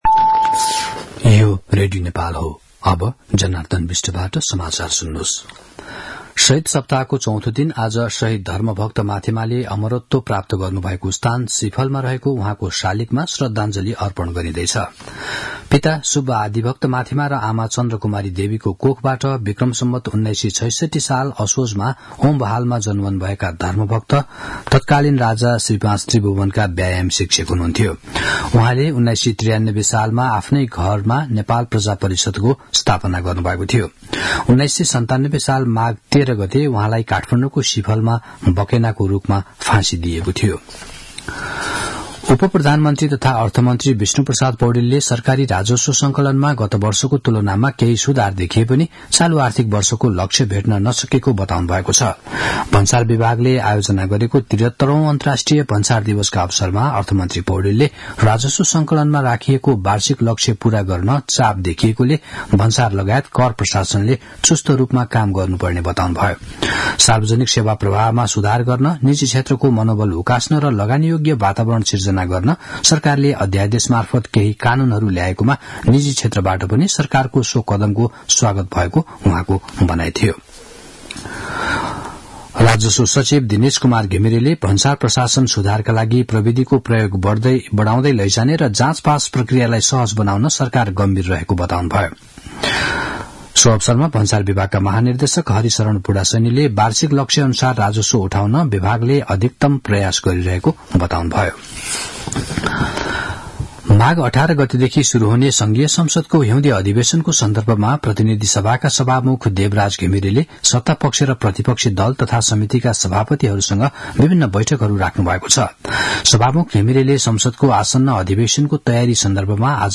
दिउँसो १ बजेको नेपाली समाचार : १४ माघ , २०८१